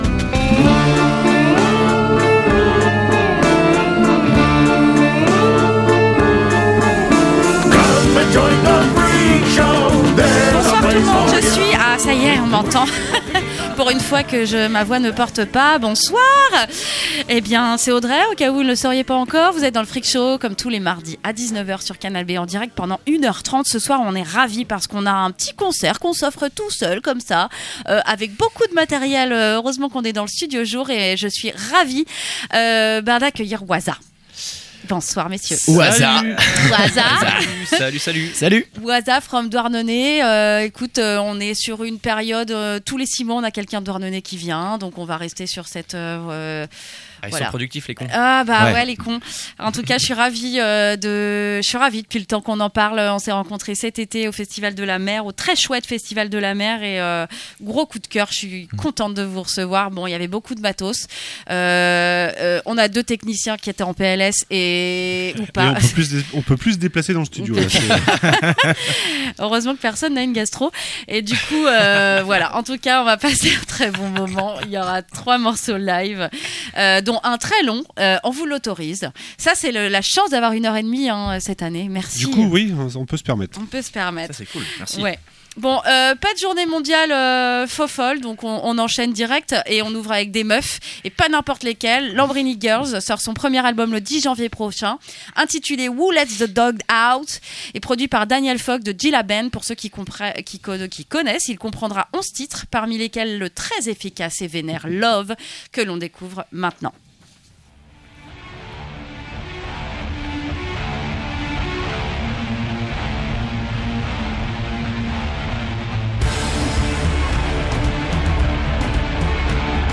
Si tu ne vas pas a Douarnenez, Douarnenez vient a toi!! les Waza sont venu en studio pour 3 morceaux Live!!